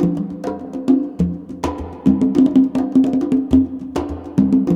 CONGBEAT12-L.wav